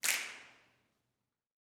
SNAPS 31.wav